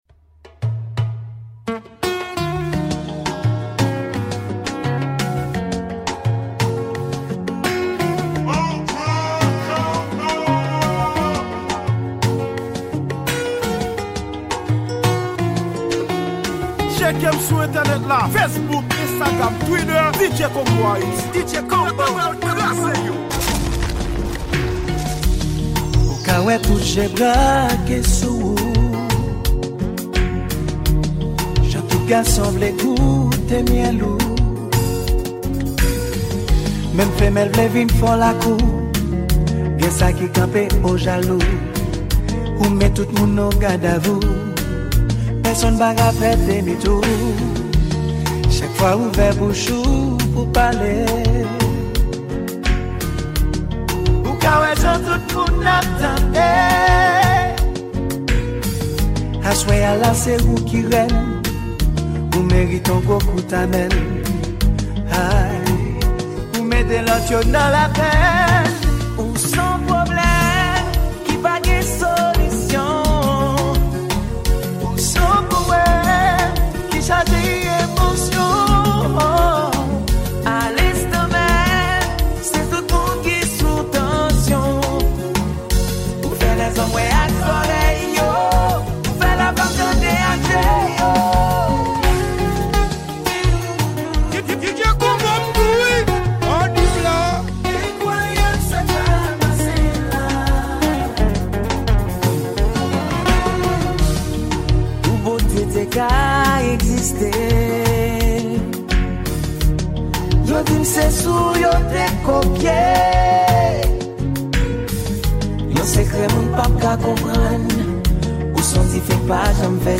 Genre: Mix